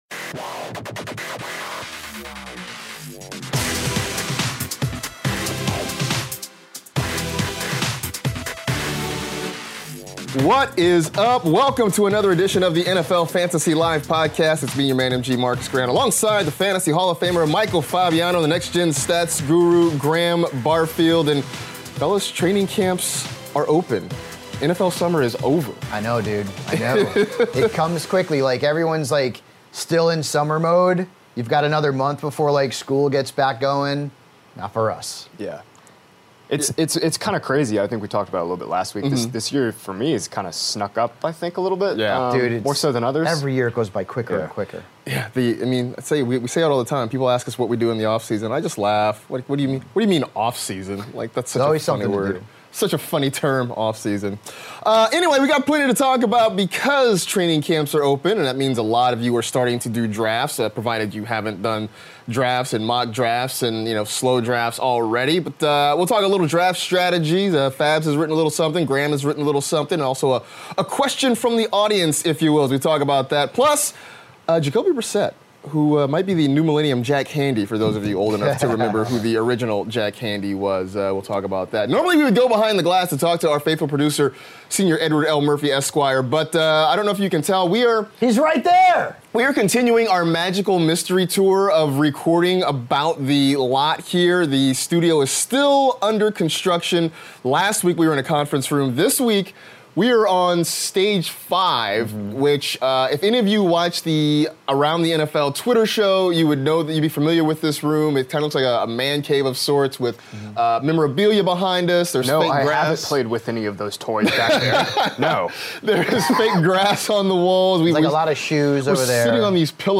back in the studio on a Tuesday for a brand new Fantasy Live Podcast!